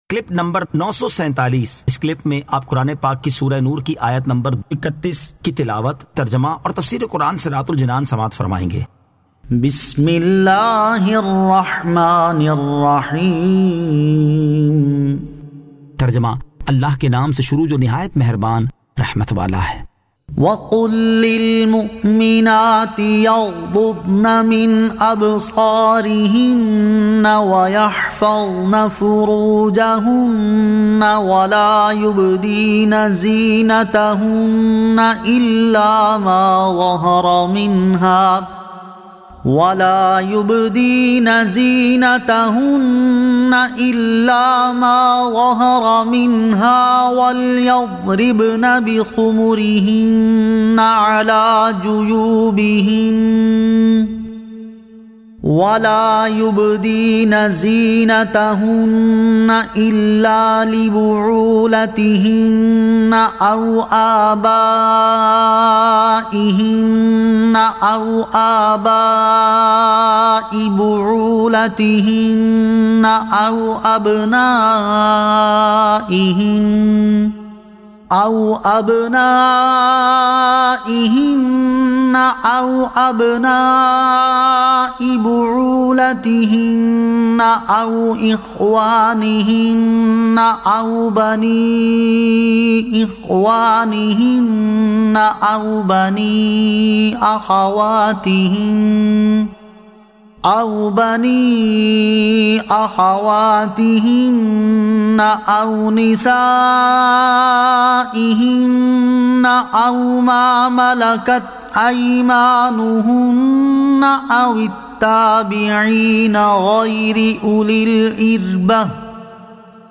Surah An-Nur 31 To 31 Tilawat , Tarjama , Tafseer